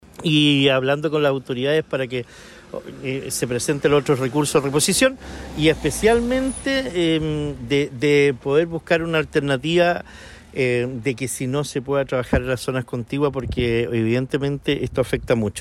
La autoridad comunal de Quellón destacó que entre otras gestiones, han recalcado que los organismos competentes presenten los recursos que permitan destrabar esta situación, como es el caso de la subsecretaría de Pesca.